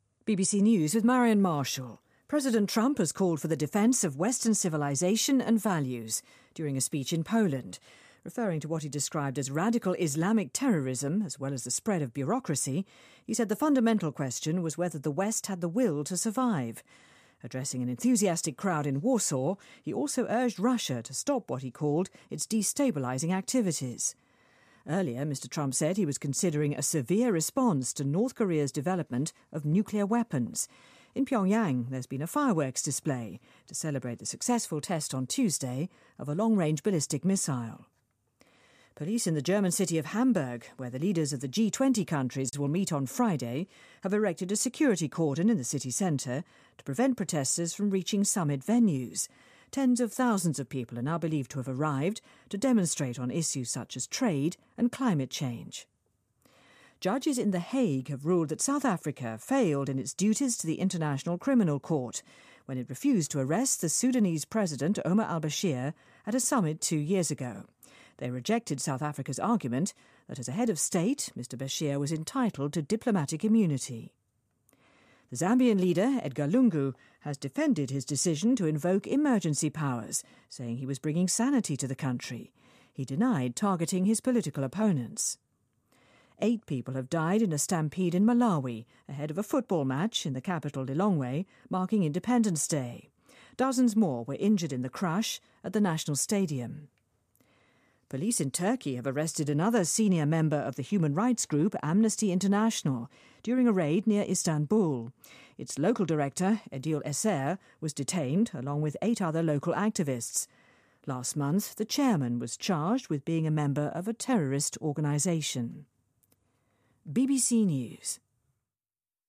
日期:2017-07-08来源:BBC新闻听力 编辑:给力英语BBC频道